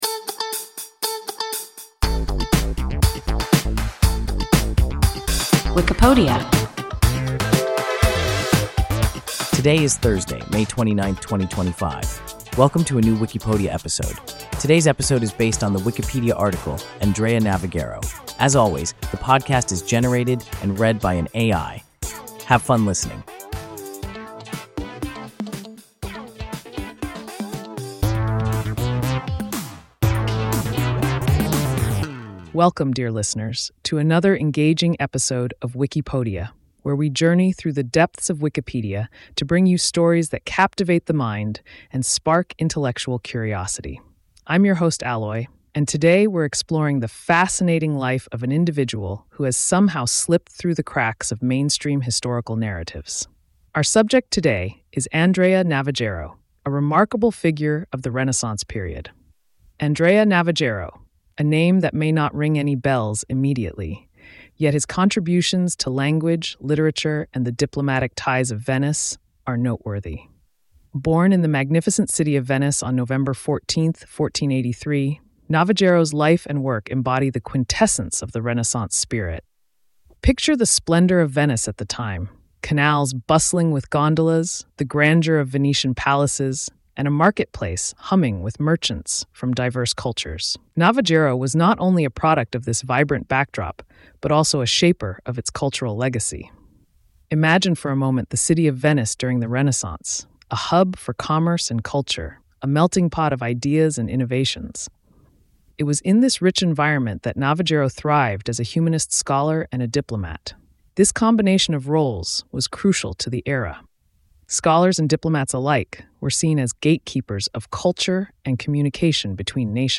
Andrea Navagero – WIKIPODIA – ein KI Podcast